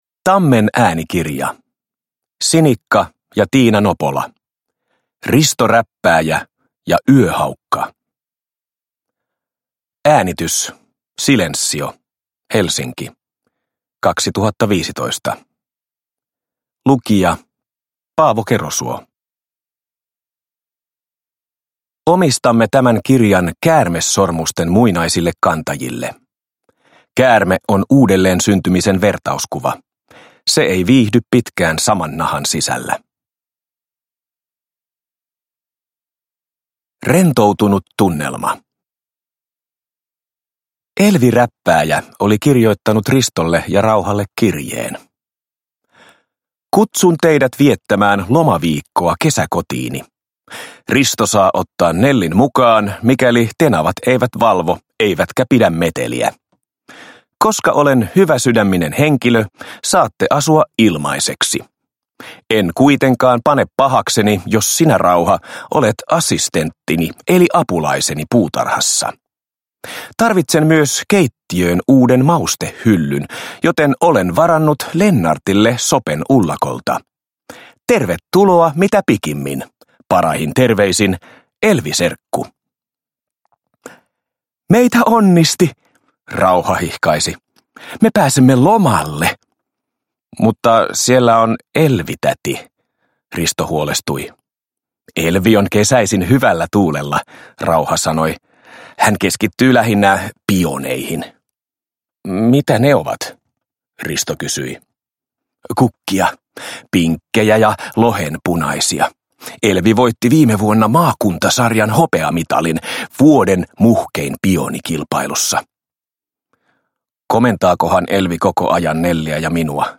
Risto Räppääjä ja yöhaukka – Ljudbok